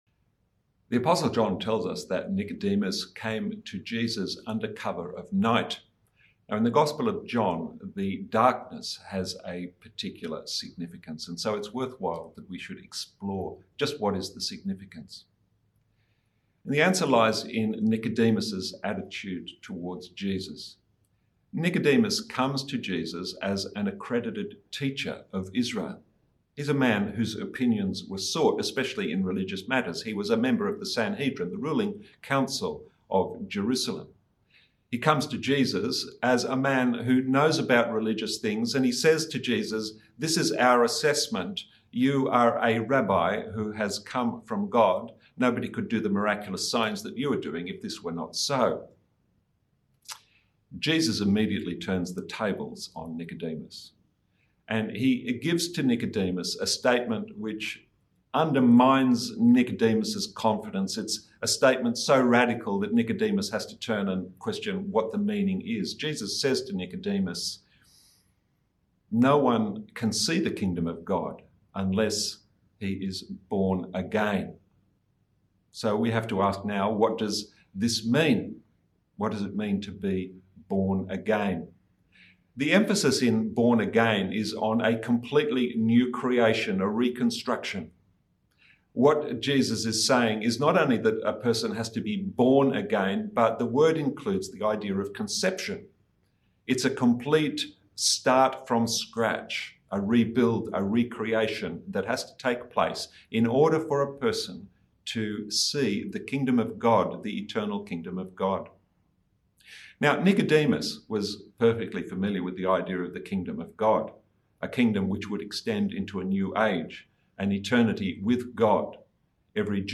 Sermons | St Johns Anglican Cathedral Parramatta